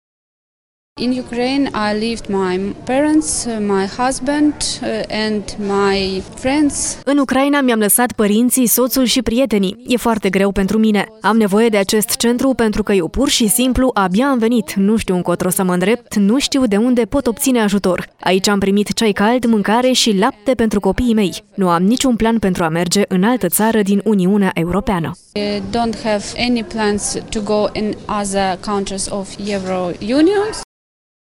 UCRAINEANCA-TRADUS.mp3